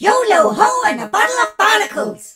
darryl_start_vo_03.ogg